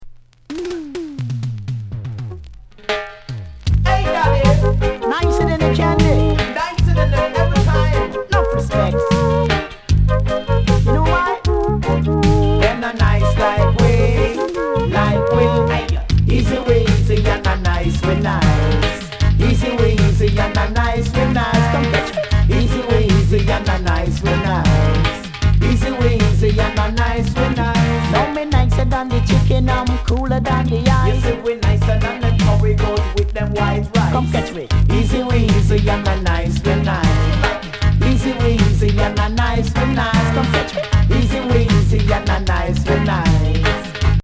Please post only reggae discussions here